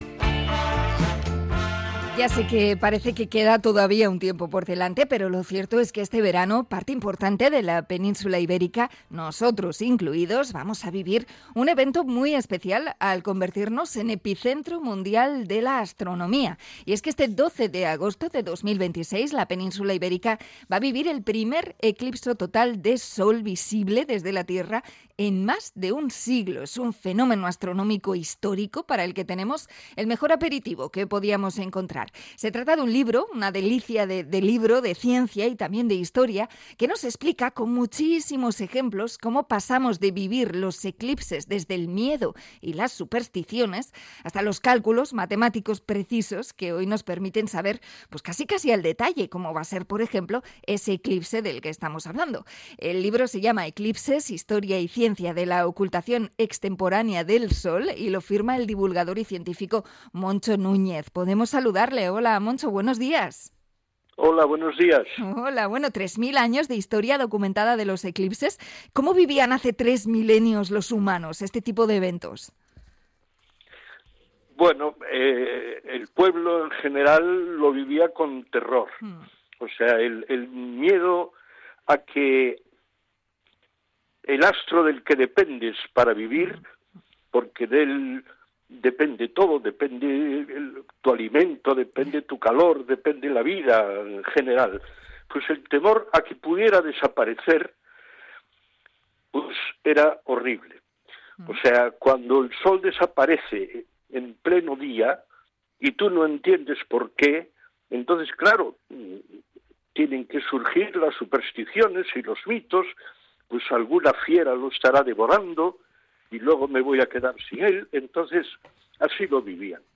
Entrevista a divulgador sobre la historia de los eclipses